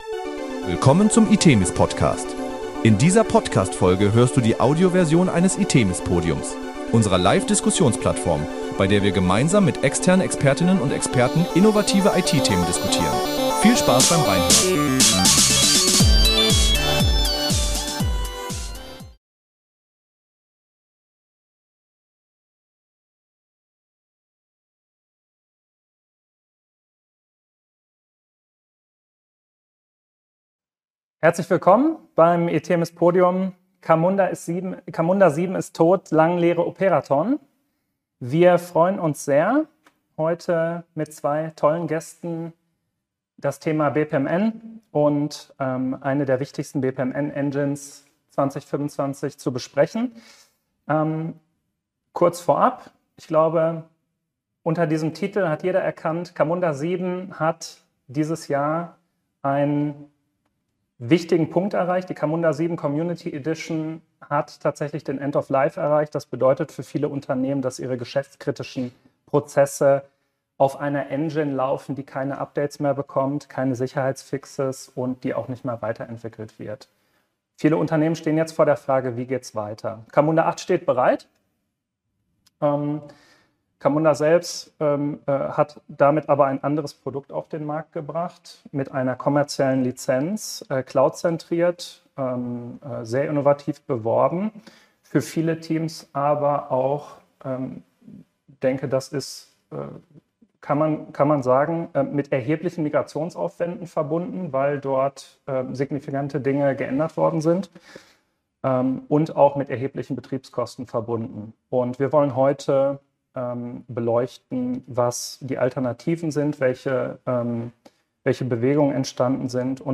In dieser Folge des itemis PODCAST hört ihr die Audioersion des itemis PODIUMS. CAMUNDA 7 ist tot – lang lebe OPERATON.